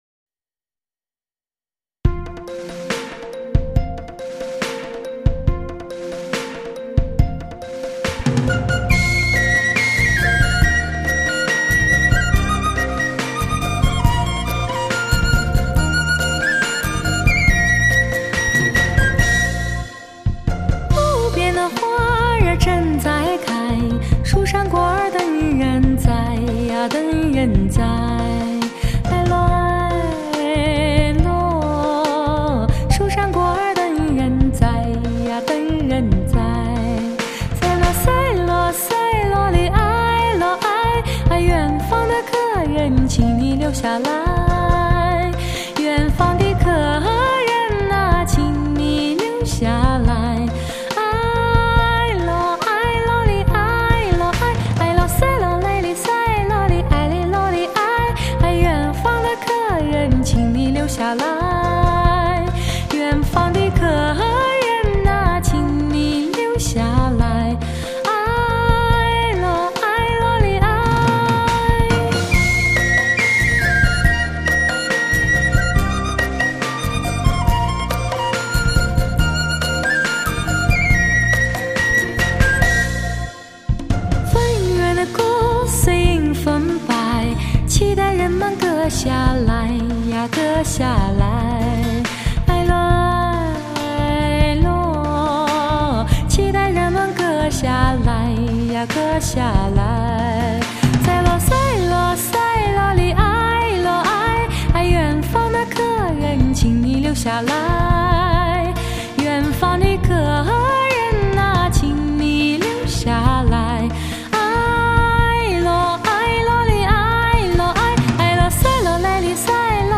音乐类型: 民乐
山歌在城市里唱响……前所未有—超声场，超动态试音大碟。
将古朴通俗的族曲风和现代时尚充满创意的视听效果结合的如此完美，更引人共鸣，情感流露越发澎湃。